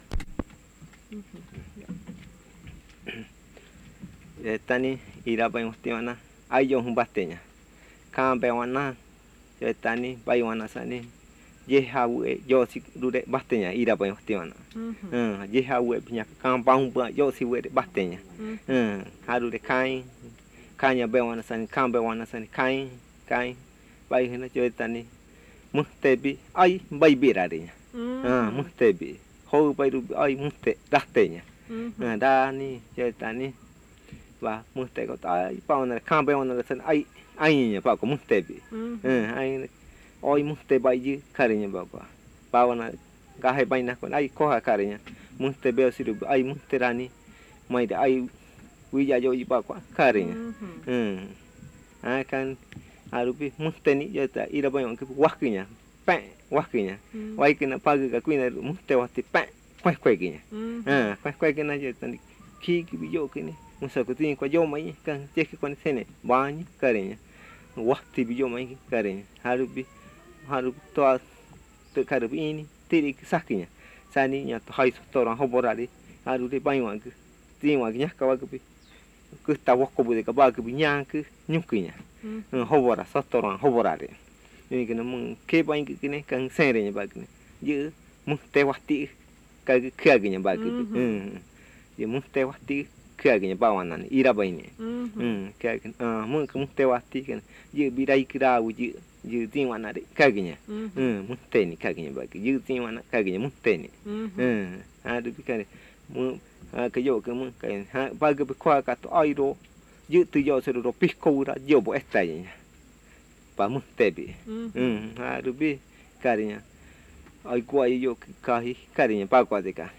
Buenavista, río Putumayo (Colombia)